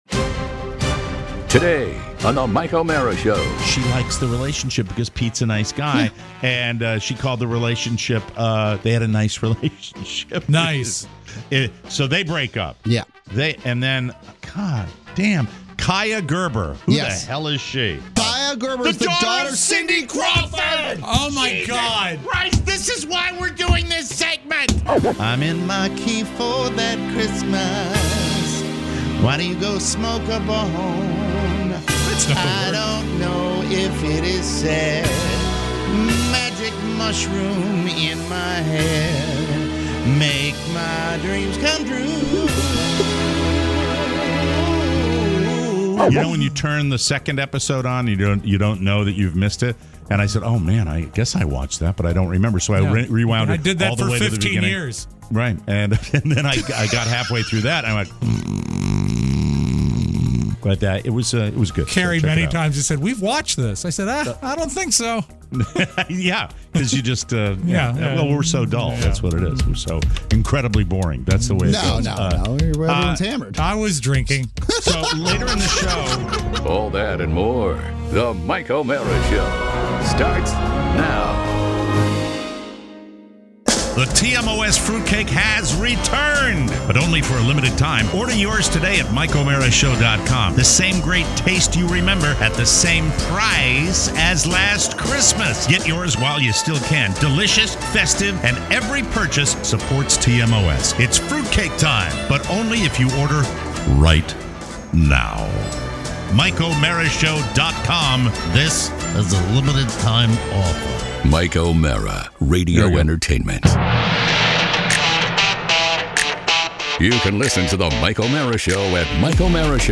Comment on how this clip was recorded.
There is construction in the hizzouse, but we press on!